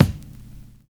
VINYL 18 BD.wav